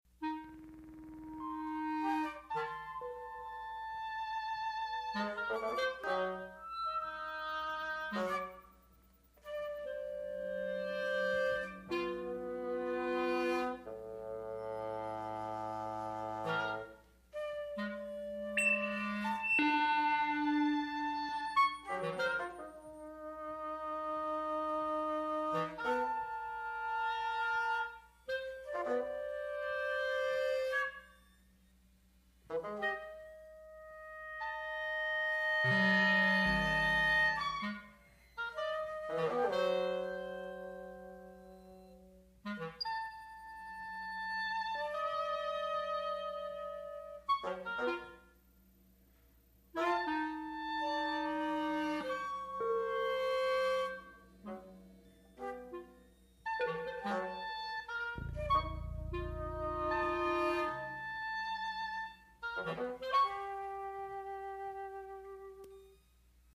(flute, oboe, clarinet, bassoon, ARP 2600 or electronics)
The score is traditionally notated and includes several improvisational sections.